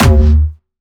Jumpstyle Kick 9
11 D#2.wav